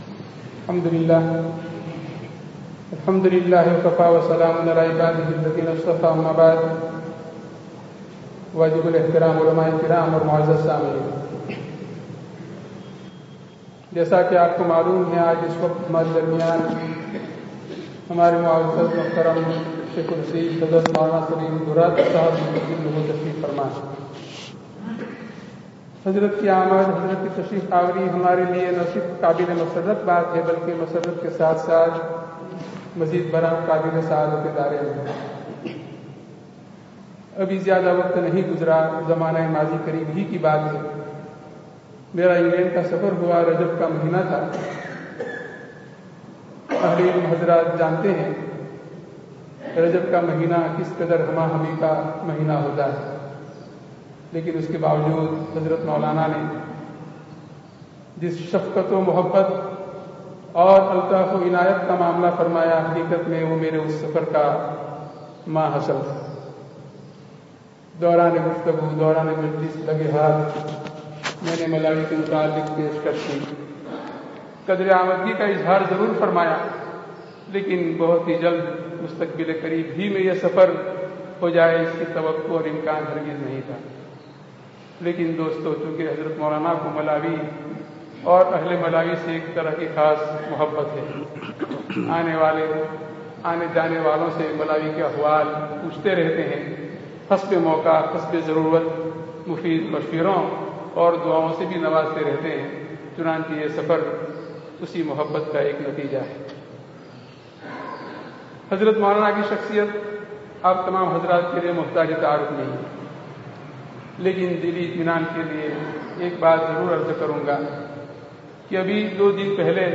Allāh ke ho Jāwo (Masjid Al Falah, Lilongwe, Malawi 13/08/18)